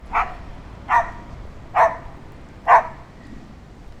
dog-dataset
puppy_0021.wav